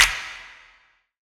DDWV POP SNAP 2.wav